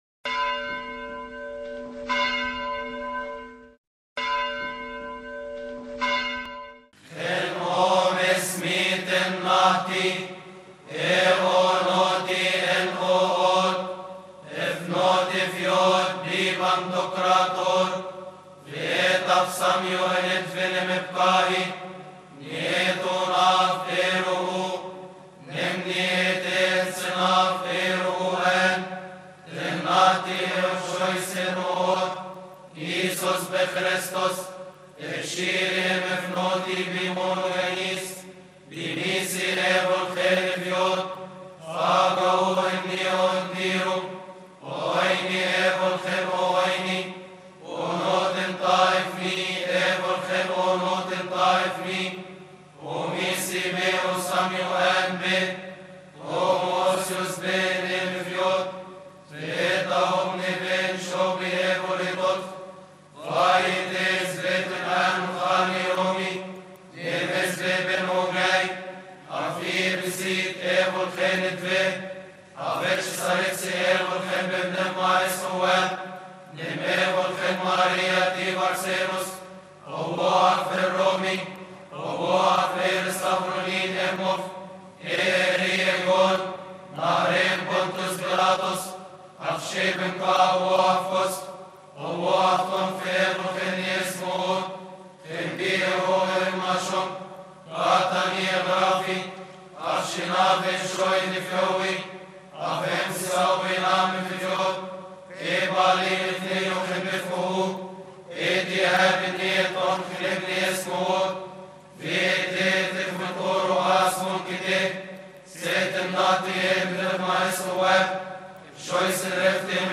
قانون الإيمان لخورس الكلية الإكليريكية اللاهوتية بدير المحرق، قبطي.
المصدر: خورس الكلية الإكليريكية اللاهوتية بدير المحرق